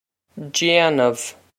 Jain-uv
This is an approximate phonetic pronunciation of the phrase.